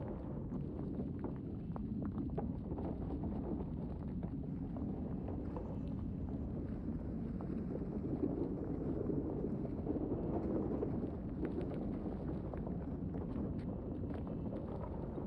base-wind-vulcanus.ogg